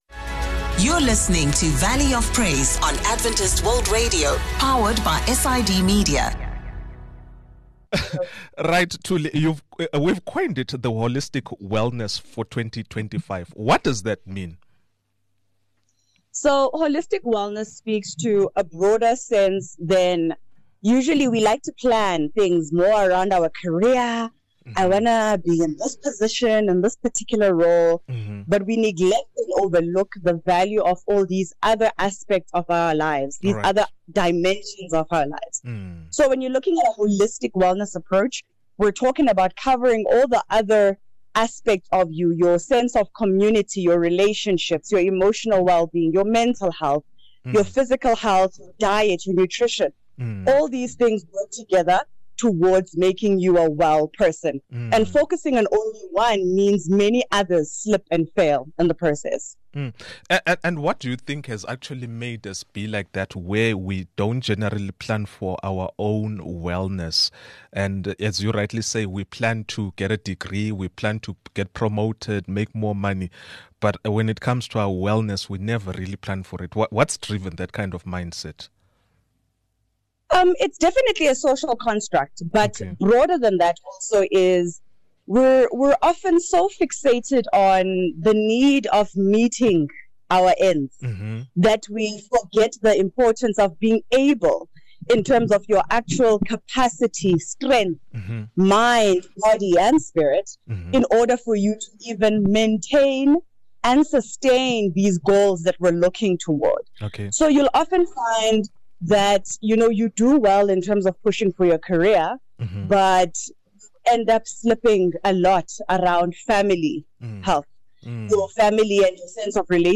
From setting achievable goals to balancing physical, mental, and spiritual health, our guest shares actionable insights and strategies to help you thrive in the year ahead. Whether you're looking to boost energy, reduce stress, or cultivate a more fulfilling life, this conversation is packed with tips to guide you toward holistic well-being.